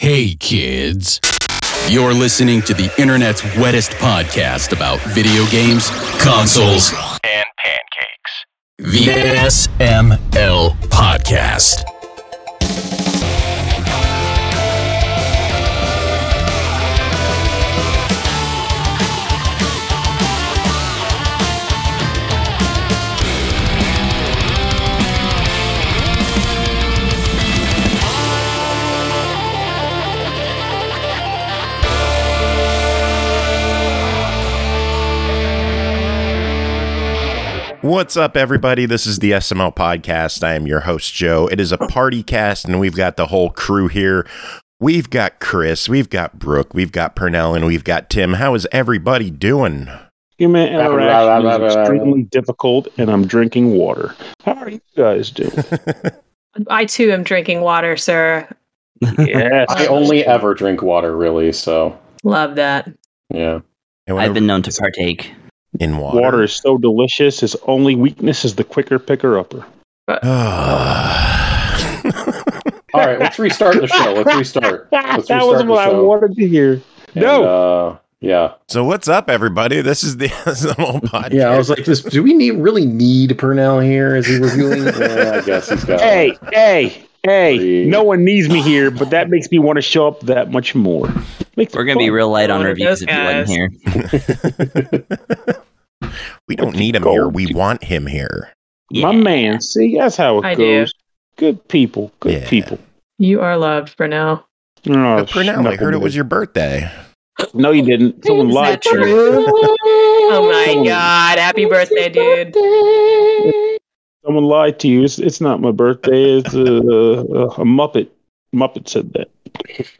It’s a PartyCast with some gentle joshing amongst friends coming up on this episode!